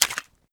Pistol_MagOut.wav